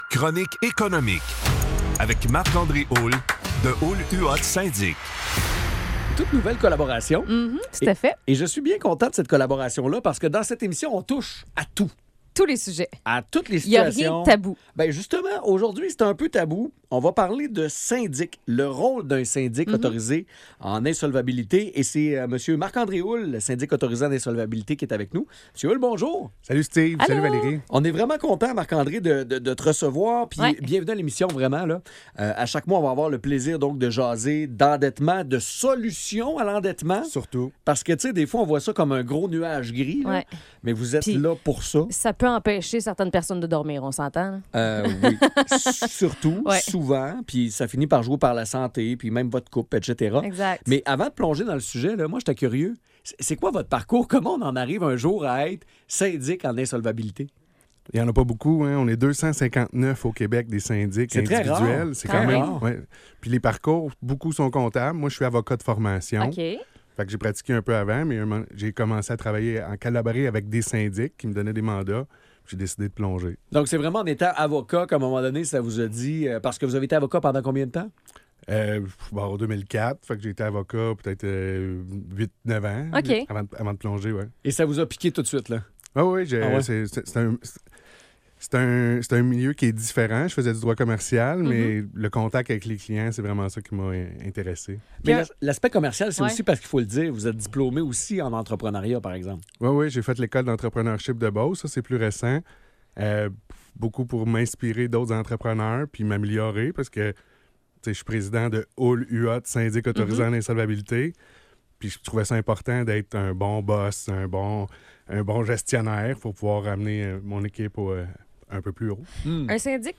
Chronique Radio: